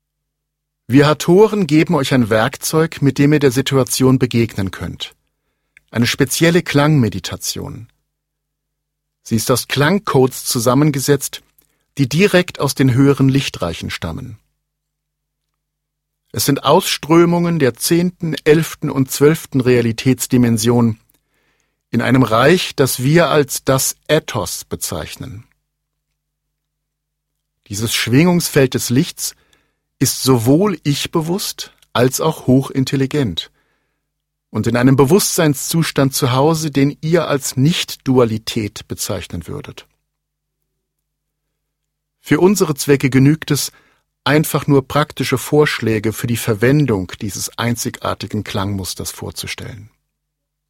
Schlagworte Destabilisierung • Energiewesen (Channelings) • Entgiftung • Hathoren • Hathoren (Esoterik) • Klangheilung • Klangtherapie • Meditation